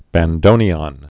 (băn-dōnē-ŏn)